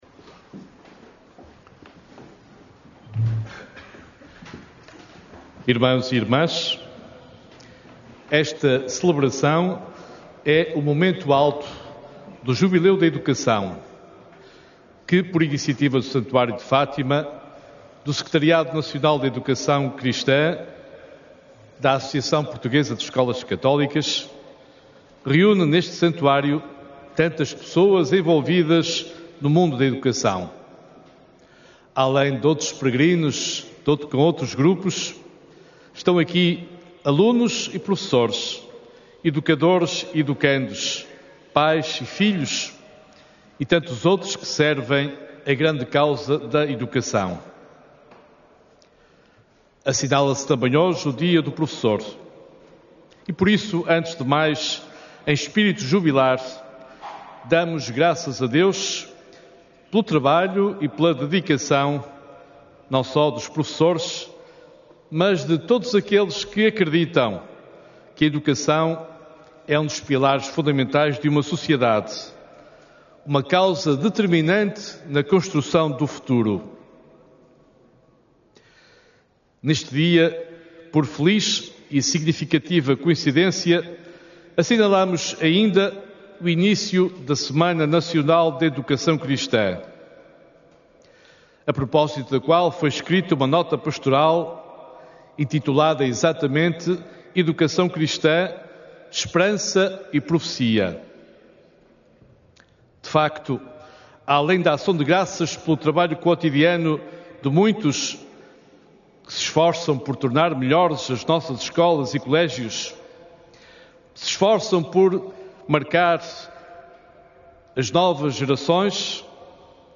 Na homilia da missa do encontro, celebrada na manhã deste domingo no Recinto de Oração do Santuário, D. António Augusto Azevedo apresentou a educação como um dos pilares fundamentais da sociedade e uma causa determinante na construção do futuro e apelou à comunidade educativa ali reunida para acreditarem na grandeza da sua missão, com um espírito de serviço e esperança.